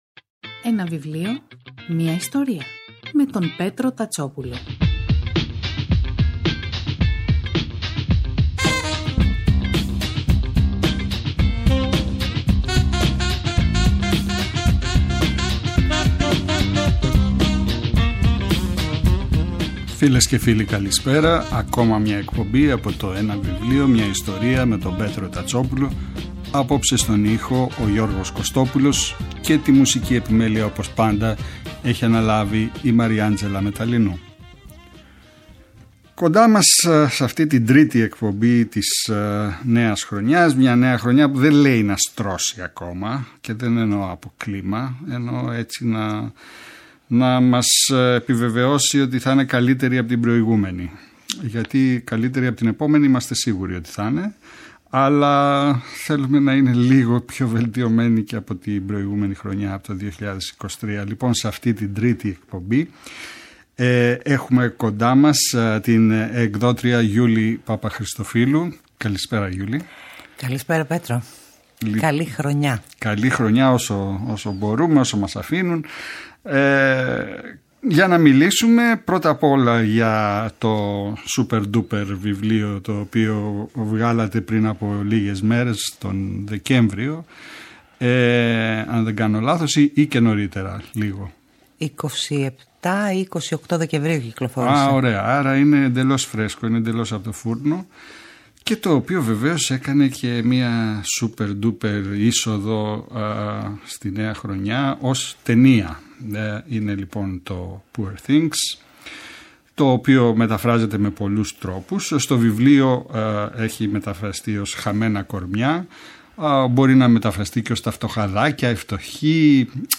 Κάθε Σάββατο και Κυριακή, στις 5 το απόγευμα στο Πρώτο Πρόγραμμα της Ελληνικής Ραδιοφωνίας ο Πέτρος Τατσόπουλος, παρουσιάζει ένα συγγραφικό έργο, με έμφαση στην τρέχουσα εκδοτική παραγωγή, αλλά και παλαιότερες εκδόσεις. Η γκάμα των ειδών ευρύτατη, από μυθιστορήματα και ιστορικά μυθιστορήματα, μέχρι βιογραφίες, αυτοβιογραφίες και δοκίμια.